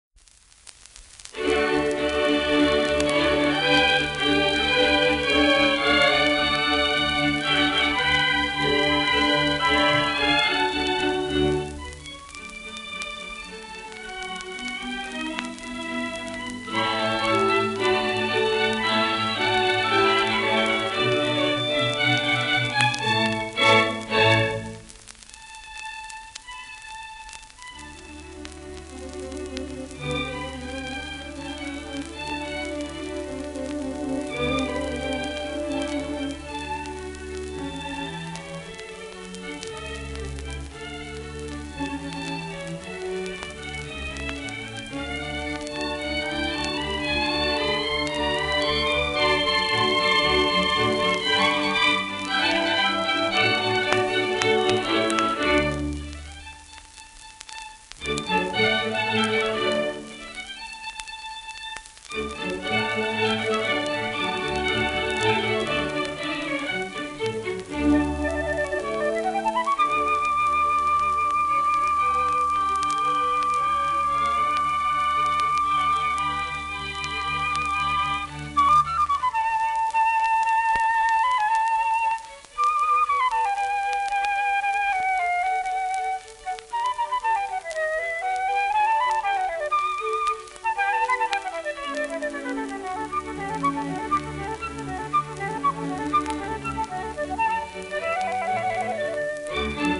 盤質A- *小キズ多少音あり
1930年録音